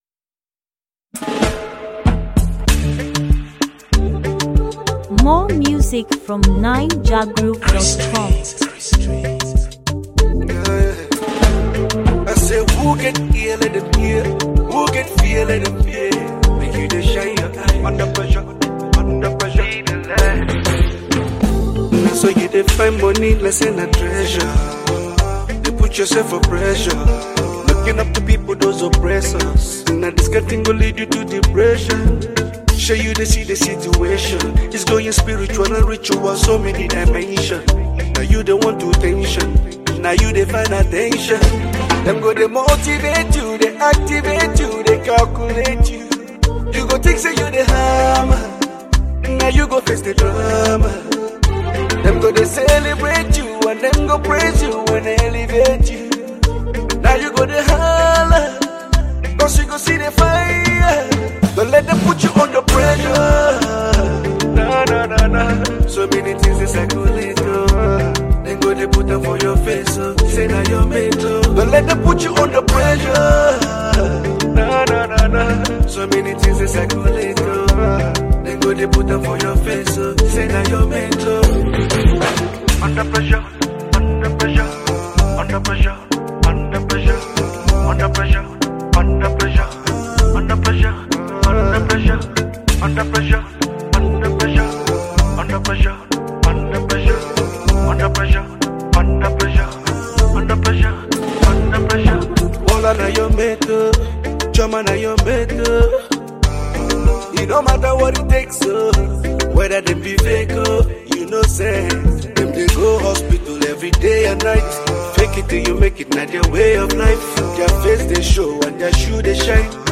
Latest, Naija-music
a gifted Nigerian singer-songwriter.
Afrobeat and R&B music